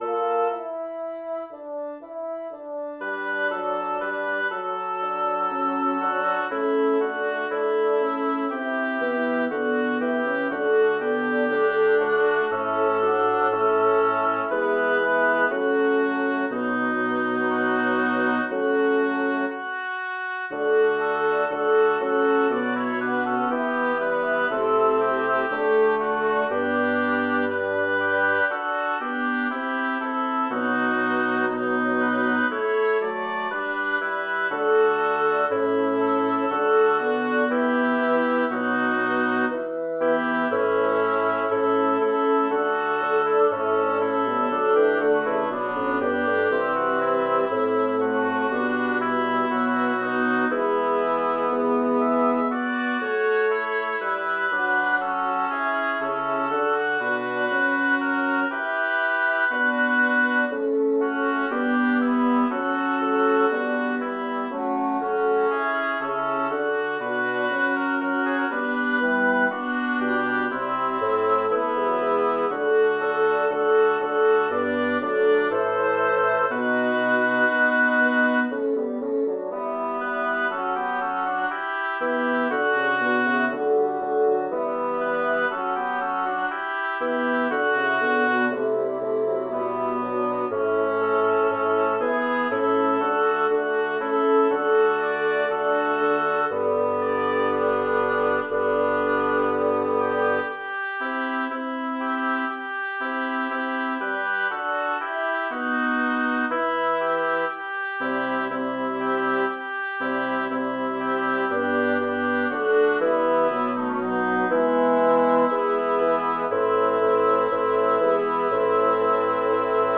Language: German Instruments: A cappella